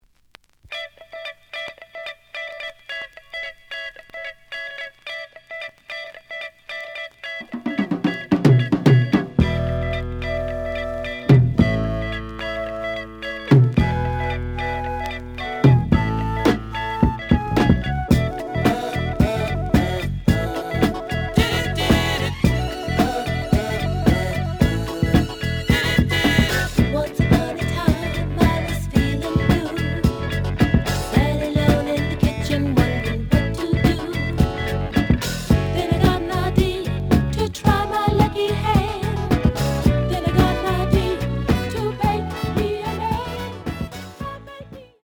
The audio sample is recorded from the actual item.
●Genre: Soul, 70's Soul
Slight noise on beginning of both sides, but almost good.